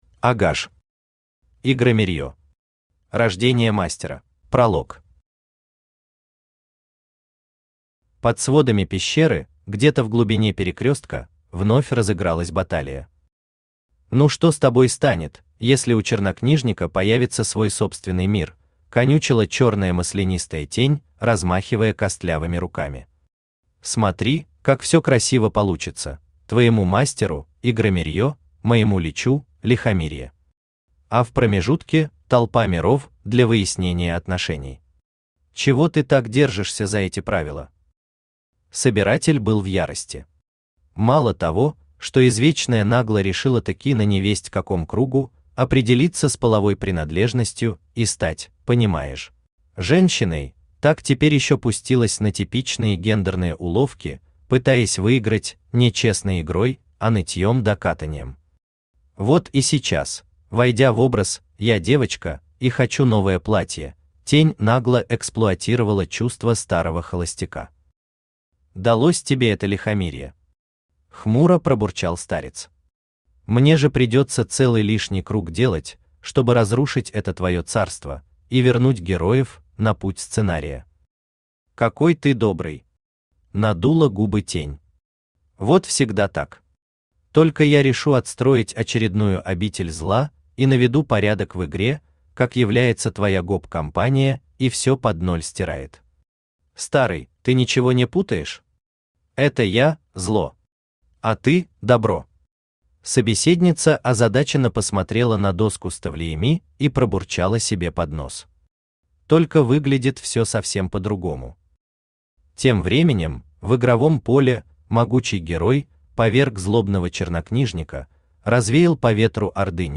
Аудиокнига Игромирье.
Aудиокнига Игромирье. Рождение Мастера Автор А Р ГАЖ Читает аудиокнигу Авточтец ЛитРес.